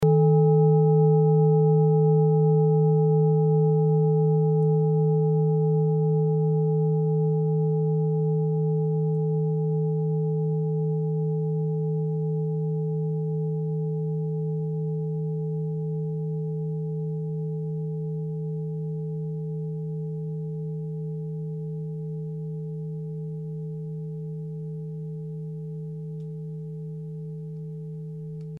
Klangschale Orissa Nr.6
Sie ist neu und wurde gezielt nach altem 7-Metalle-Rezept in Handarbeit gezogen und gehämmert.
Hören kann man diese Frequenz, indem man sie 32mal oktaviert, nämlich bei 154,66 Hz. In unserer Tonleiter befindet sich diese Frequenz nahe beim "D".
klangschale-orissa-6.mp3